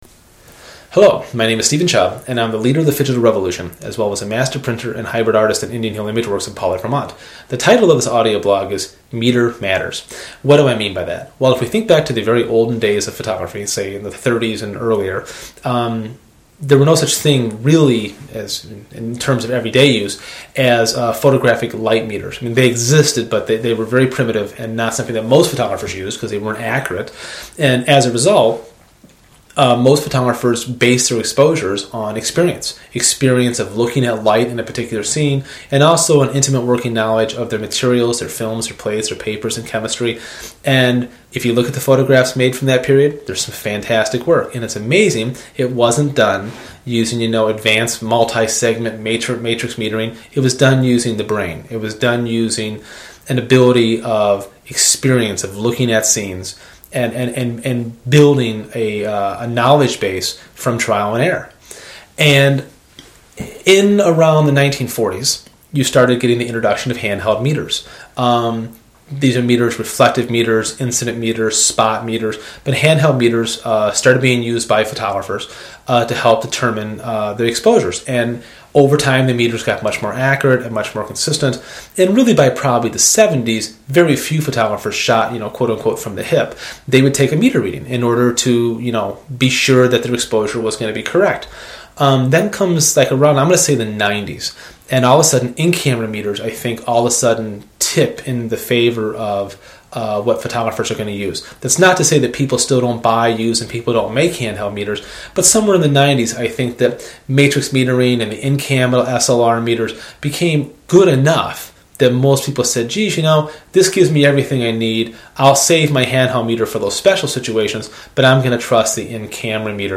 This audioblog focuses on the idea of building a knowledge base for a better understanding of light, material limitations and proper exposure.